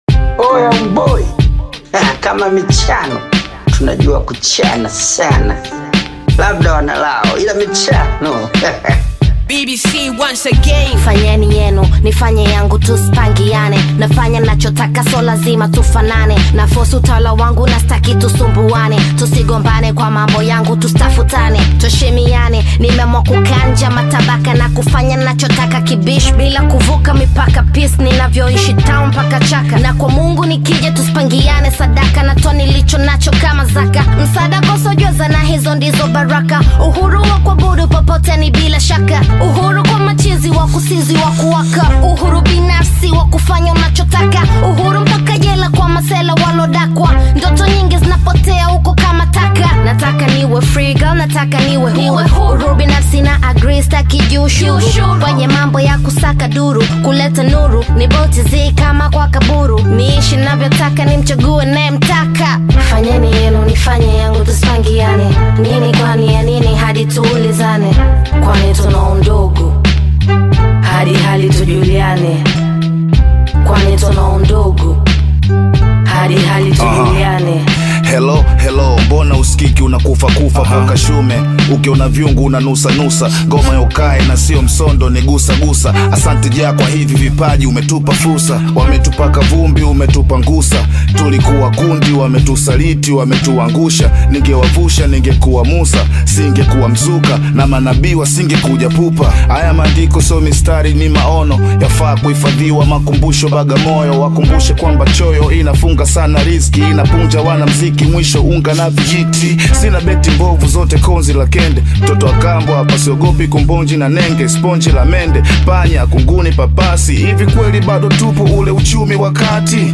Tanzanian hip hop
a powerful brand new hip hop song